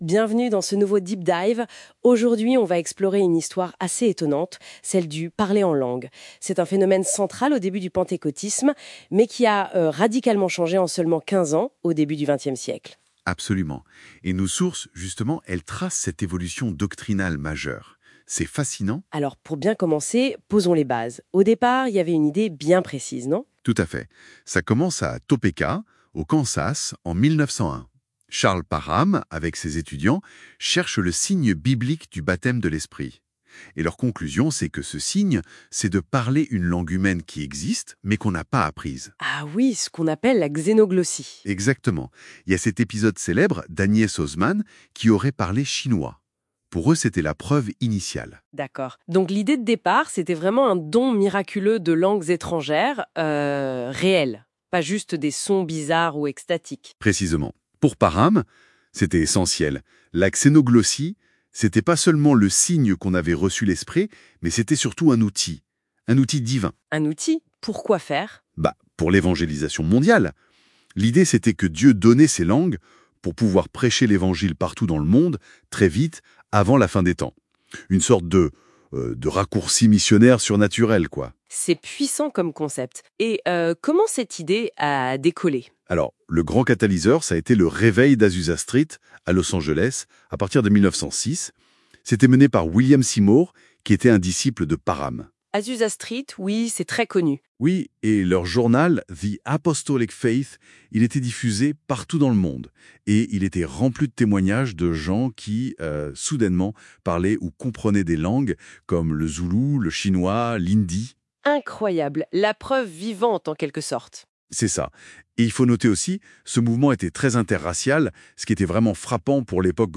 Une Intelligence Artificielle retrace l'évolution du concept